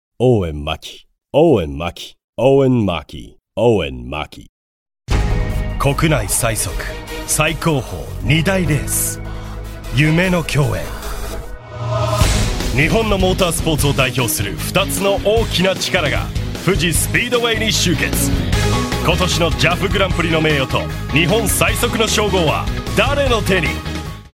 映画予告・イベント告知等